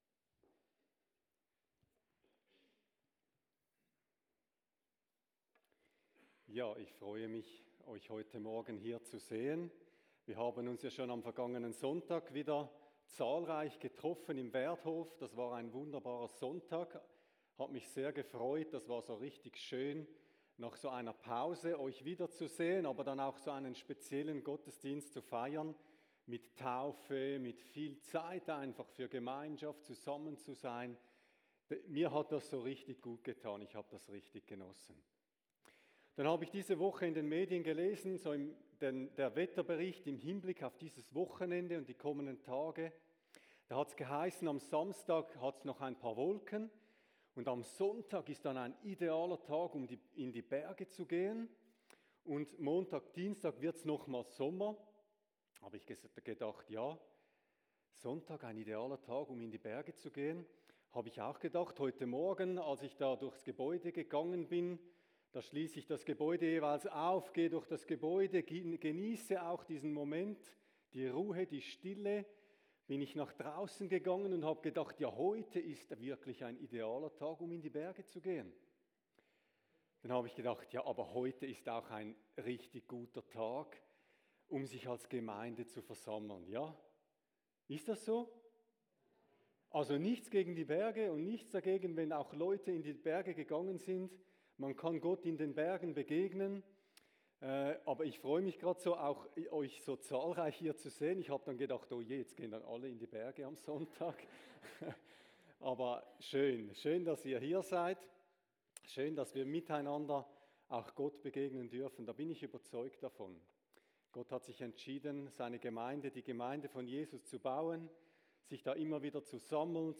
Predigt-24.8.25.mp3